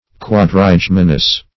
\Quad`ri*gem"i*nous\, a. [Quadri- + L. gemini twins.]
quadrigeminous.mp3